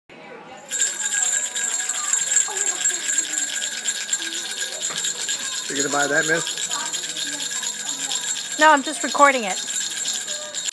sleigh-bells-in-july.m4a